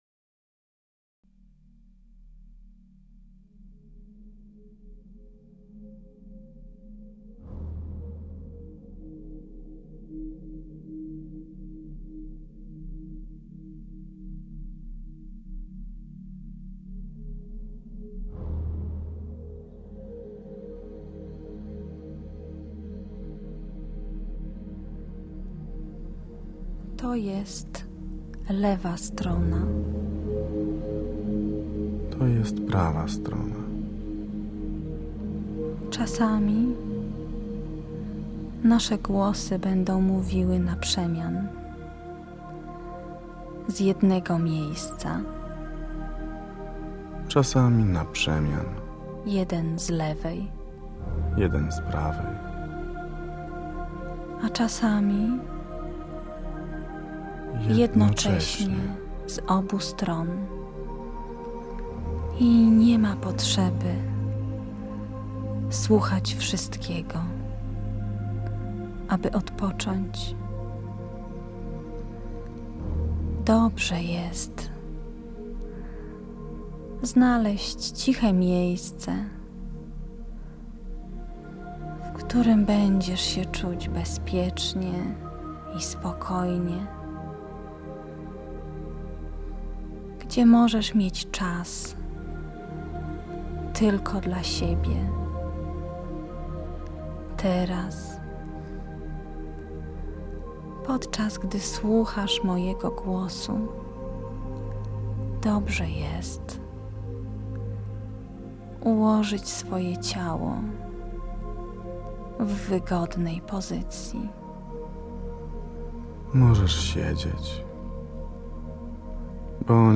Należy go słuchać w słuchawkach stereofonicznych, ponieważ rozmieszczenie przestrzenne głosów jest istotne.